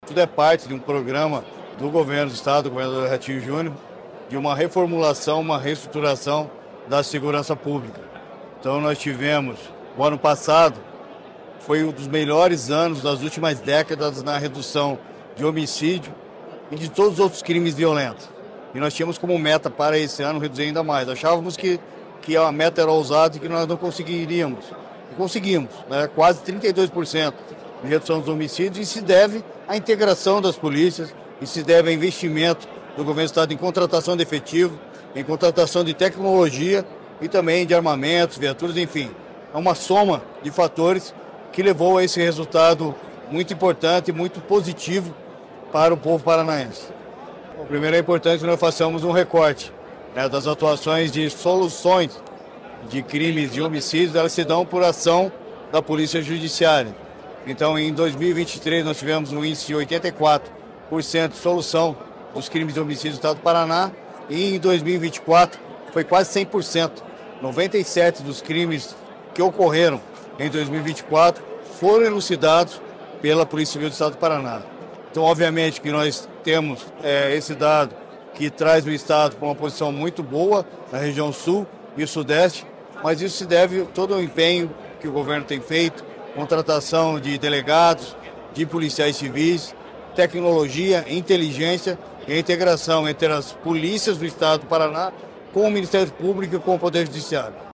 Sonora do secretário da Segurança Pública, Hudson Leôncio Teixeira, sobre a queda de homicídios dolosos no Paraná